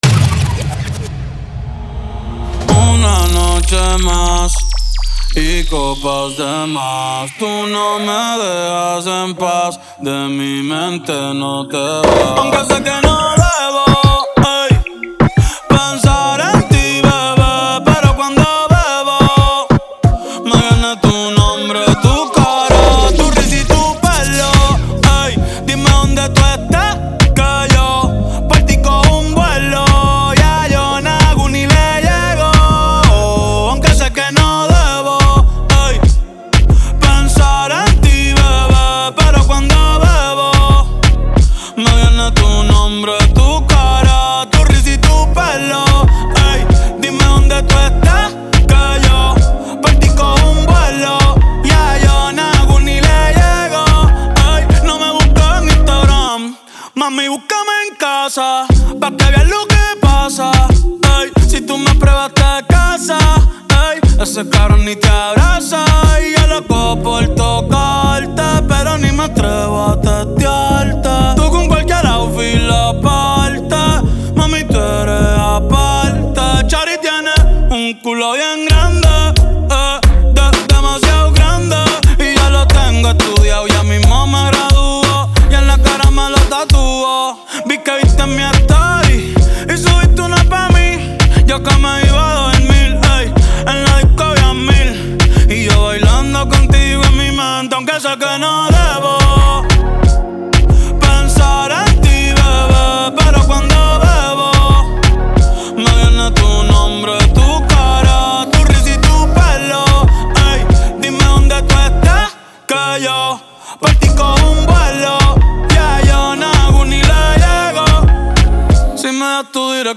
Genre: Urban Pop.